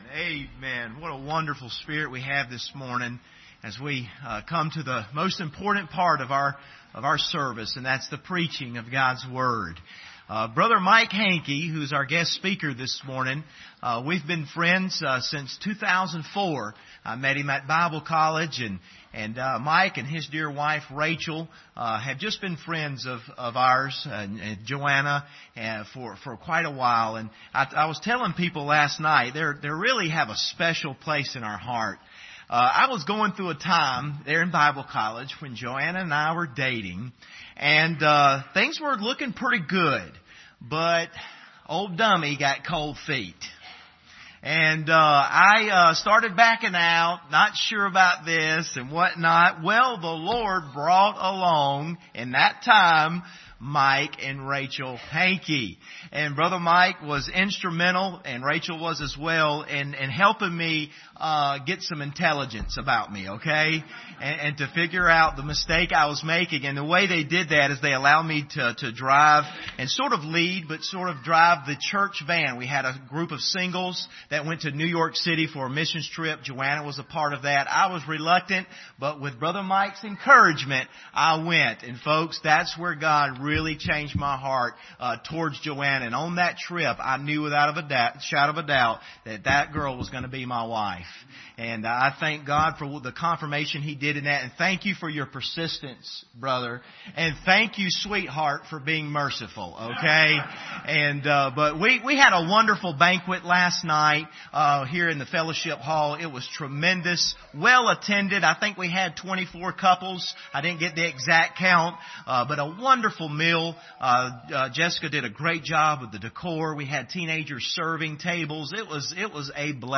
Bible Text: Psalm 46:1-11 | Preacher